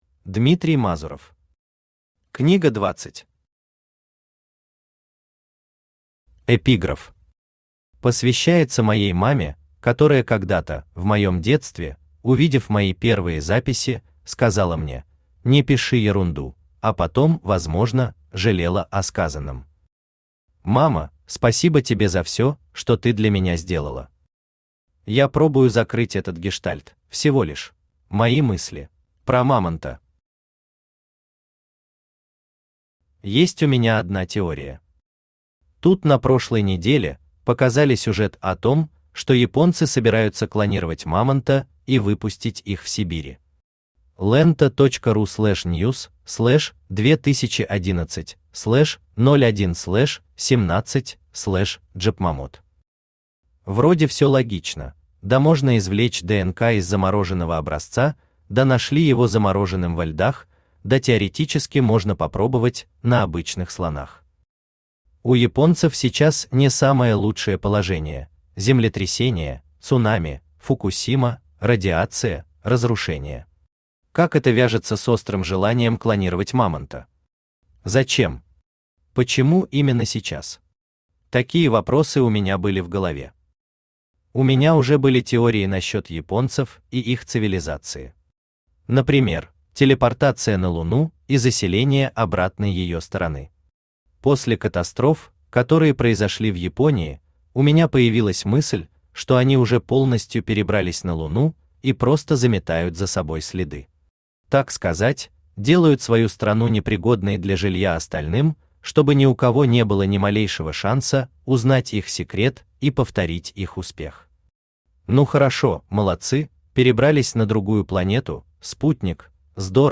Aудиокнига Книга 20 Автор Дмитрий Олегович Мазуров Читает аудиокнигу Авточтец ЛитРес.